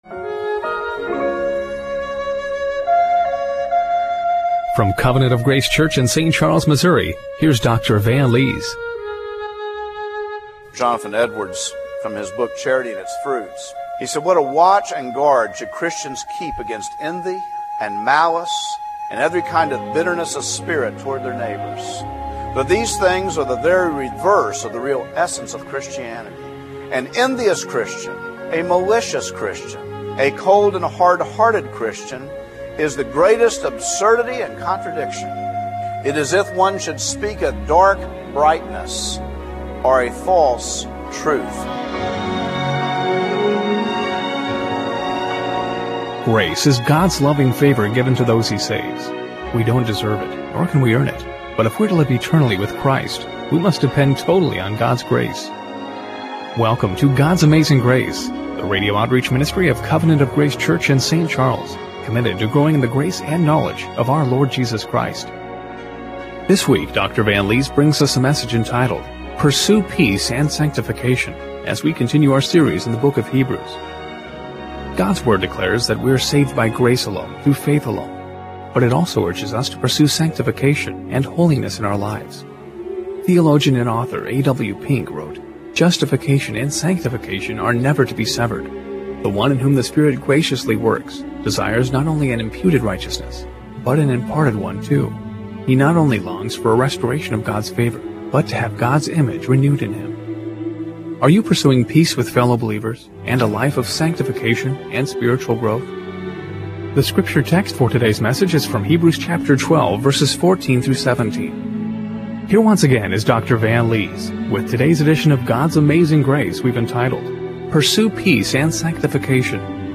Hebrews 12:14-17 Service Type: Radio Broadcast Are you pursuing peace with fellow believers